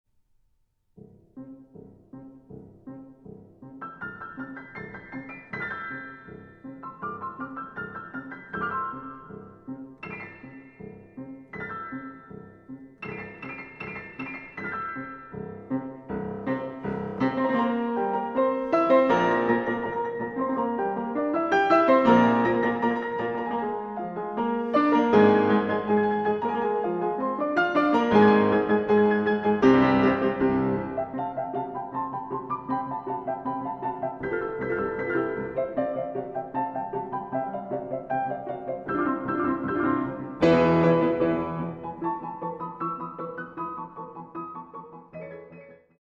arr. for piano 4 hands by composer
(Allegretto vivace)